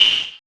Energy Hit 01.wav